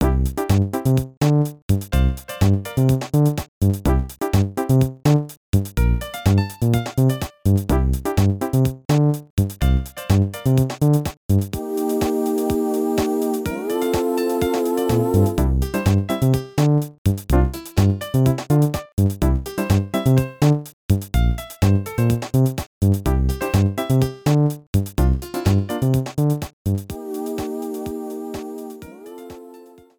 The options screen music
Cropped to 30 seconds, fade out added